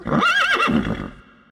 UI_Point_Horse.ogg